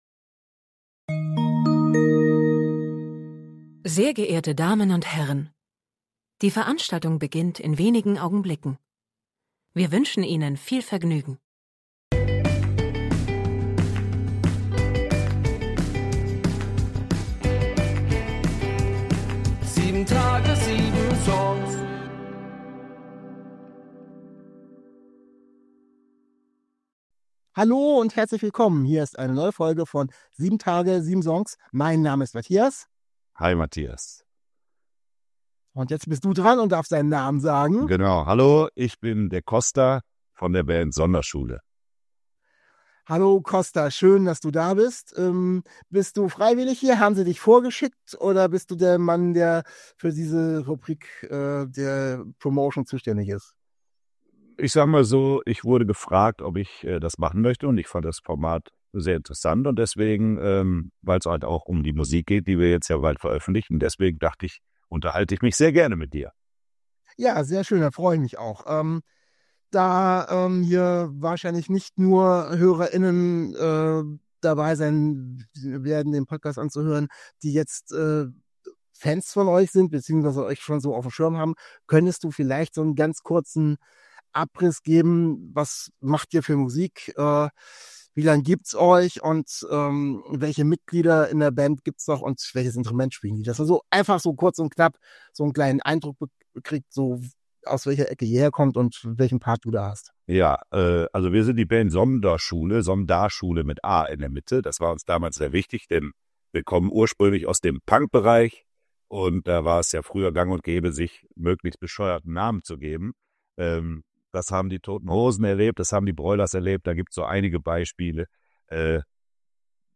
In einem sehr informativen und unterhaltsamen Gepräch erzählt er von den Songs des neuen Albums und gibt dabei auch ein paar Anekdoten preis.